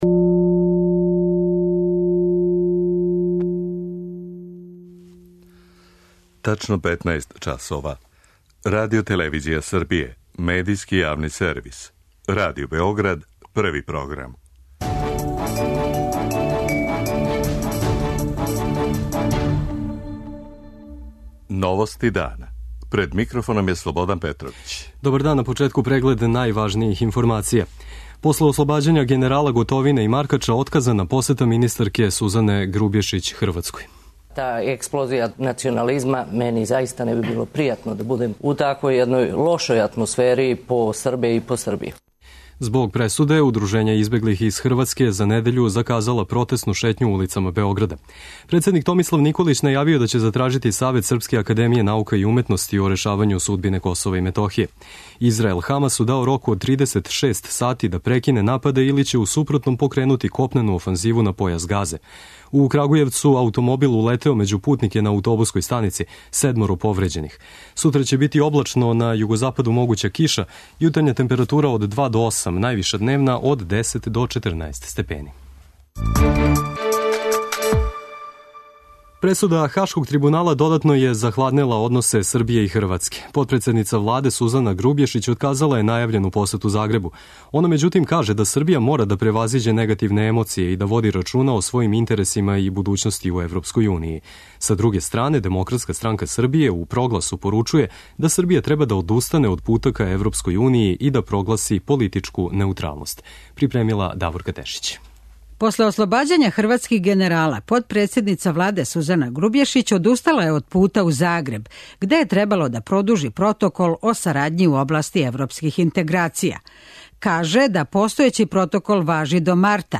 Радио Београд 1, 15.00